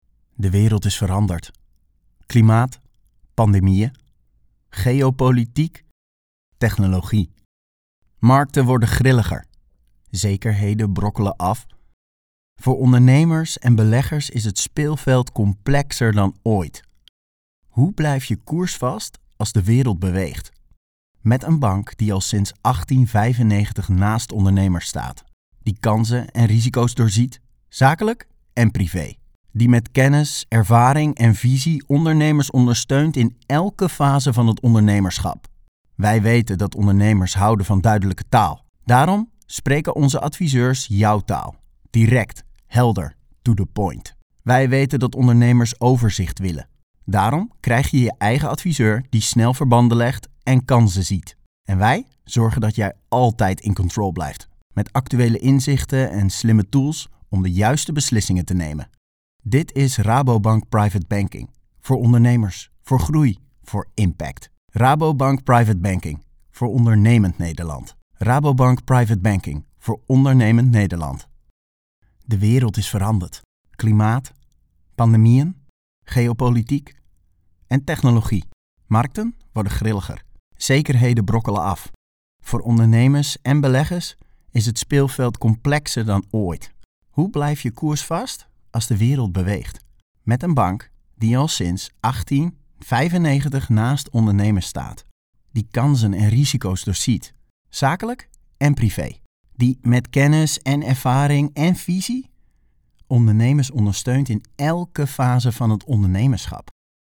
Male
20s, 30s, 40s
Energetic, Funny, Reassuring, Upbeat, Versatile, Witty
Voice reels
Microphone: Rode NT1
Audio equipment: Sound proof recording studio